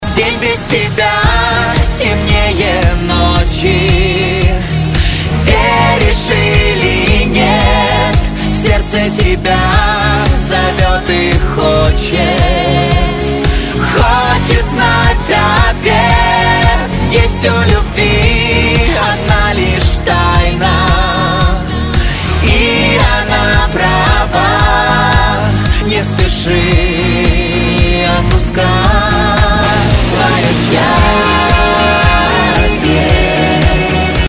Спокойные рингтоны